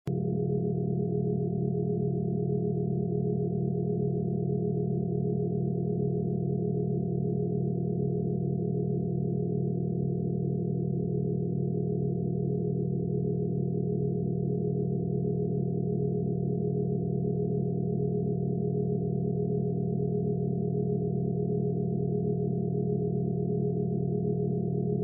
Get Super FOCUS and Intelligence with 40 hz (Binaural Beats)